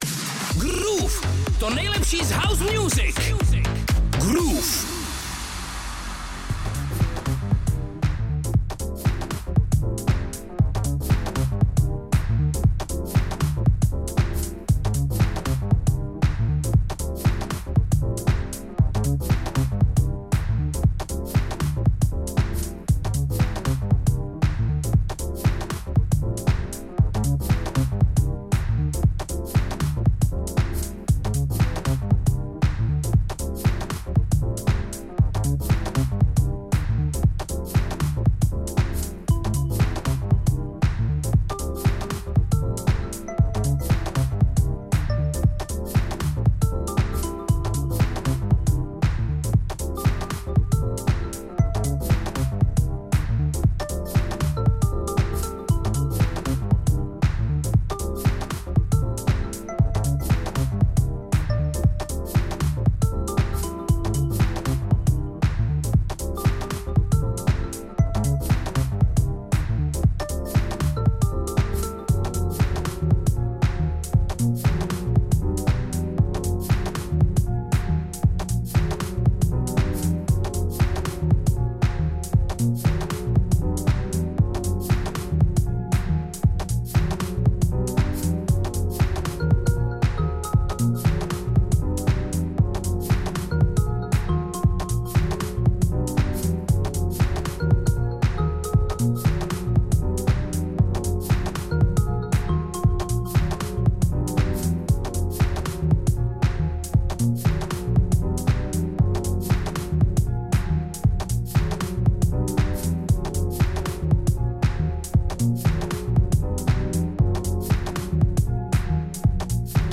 Deep house music (vinyl set)